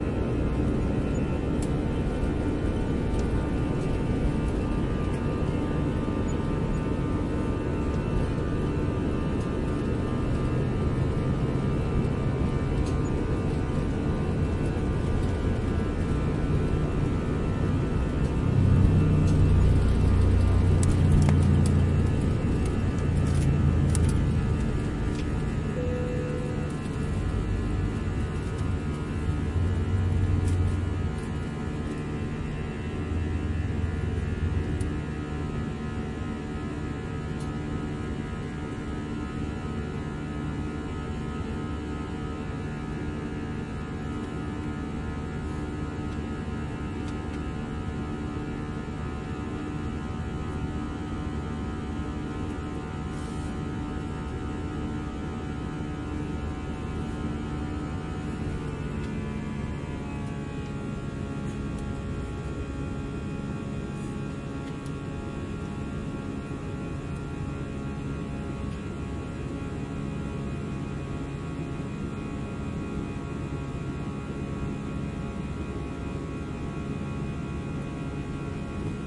机器嗡嗡声
描述：来自电动清洗机压缩机的响亮，嗡嗡的嗡嗡声。使用Roland DR20动圈麦克风录制在Marantz PMD660上。
Tag: 电垫圈 机械 电机 机械 压缩机 机器 工厂 嗡嗡声 嗡嗡声 发电机 工业 发动机